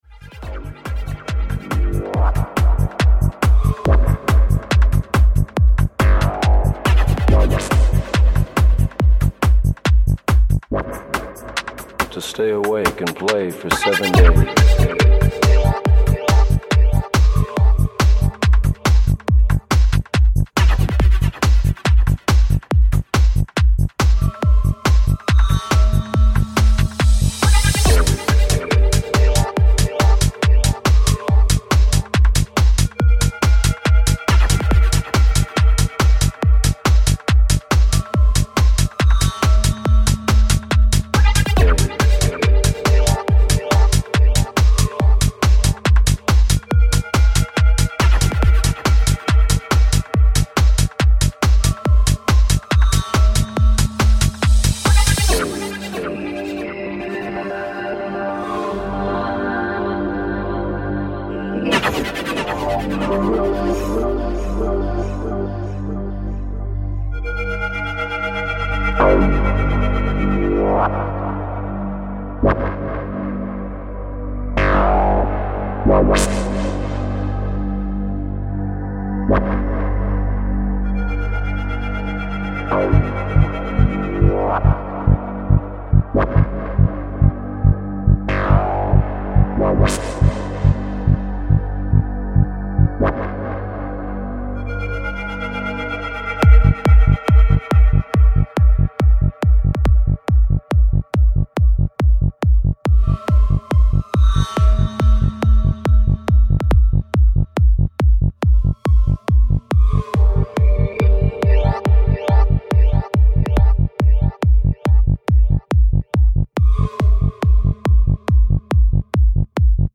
Жанр: Electronica-Experimental